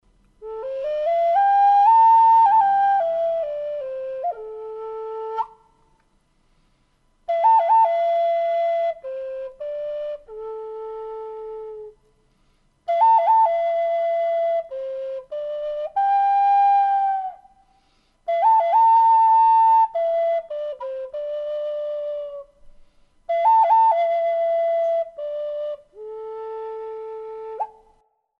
Пимак пластиковый A
Пимак пластиковый A Тональность: A
Пимак или "флейта любви" является национальным духовым инструментом североамериканских индейцев(NAF - native american flute).
Строй пентатоника ля-минор.